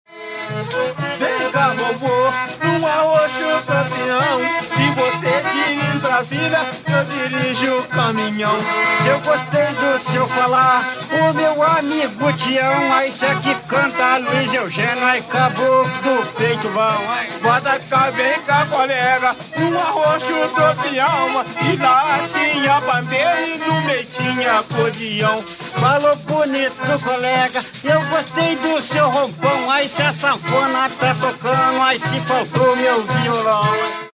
Calango (atividade musical)
Atividade musical de caráter lúdico, composta pela improvisação de versos sobre melodias conhecidas a partir de esquemas de rimas previamente estabelecidos. Executada na forma de solo/coro, acompanhada por sanfona e pandeiro. O solista canta uma quadra improvisada, e o coro repete o refrão.
calango.mp3